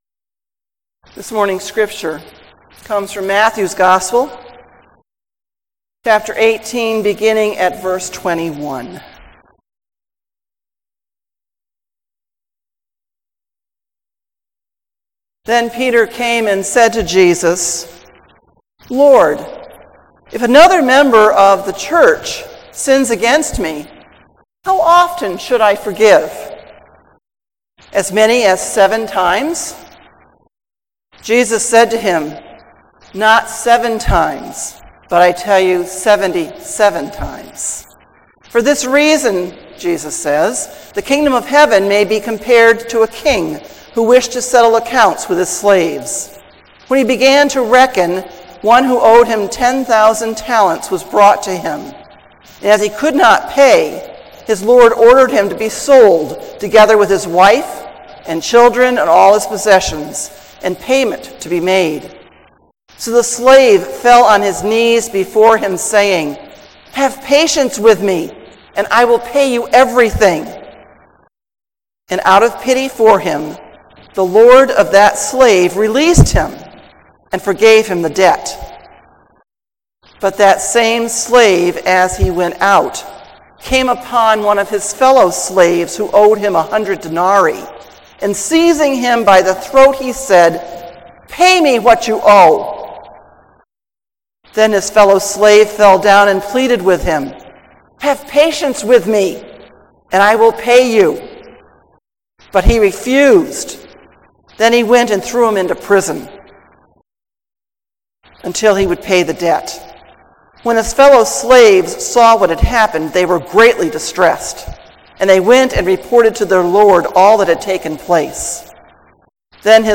Sermon: The Practice Of Forgiveness | First Baptist Church, Malden, Massachusetts